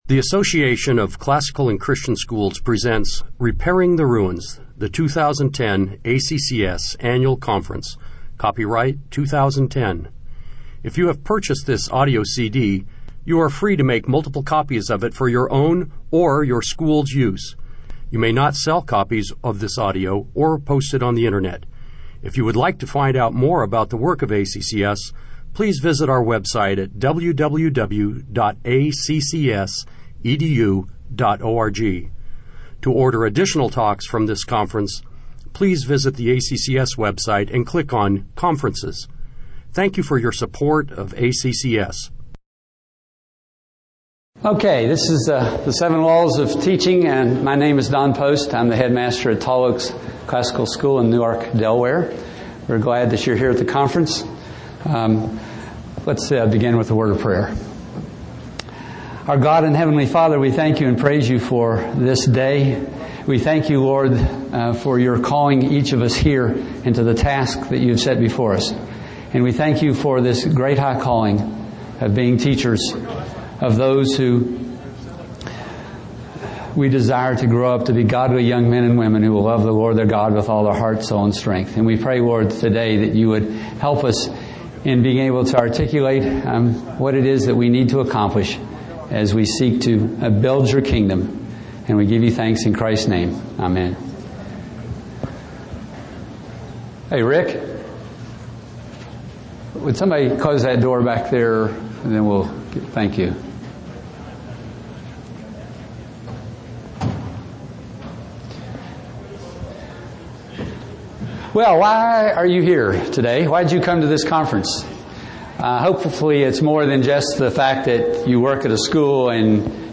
2010 Workshop Talk | 0:59:24 | All Grade Levels, Teacher & Classroom, Training & Certification
The Association of Classical & Christian Schools presents Repairing the Ruins, the ACCS annual conference, copyright ACCS.